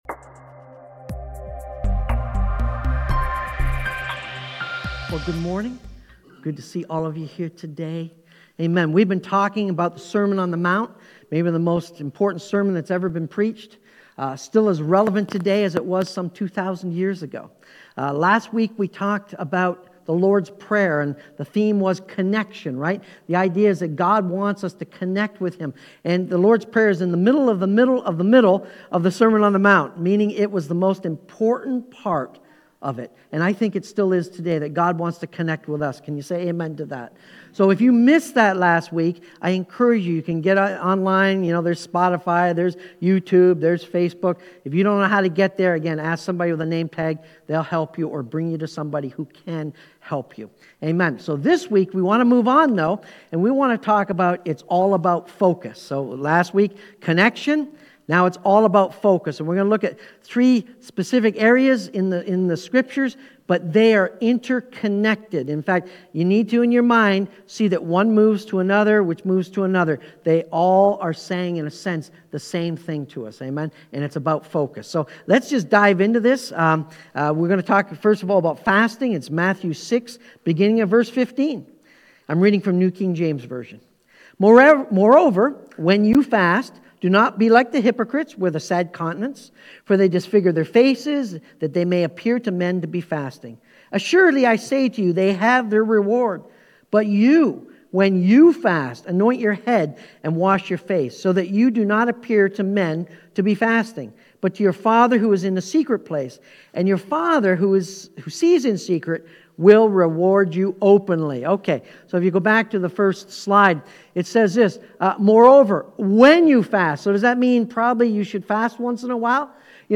Sermon-On-The-Mount-Its-All-About-Our-Focus.mp3